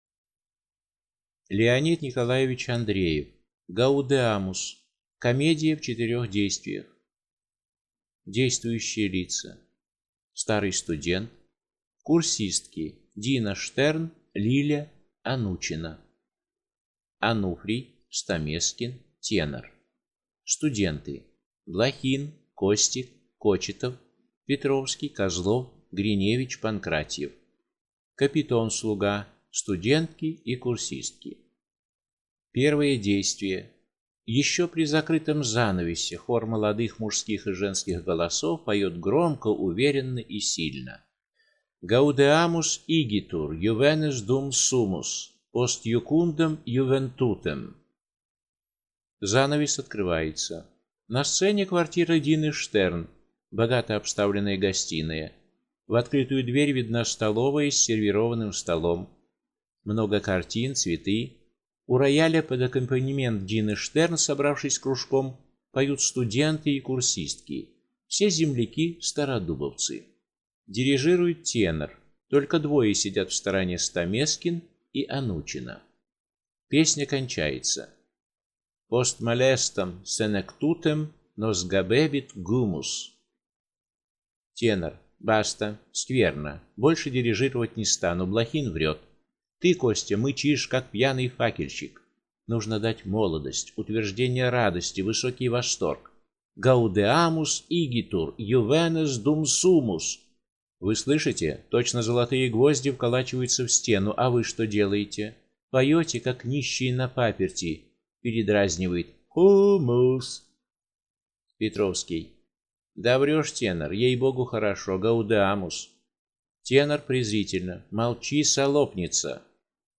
Аудиокнига «Gaudeamus» | Библиотека аудиокниг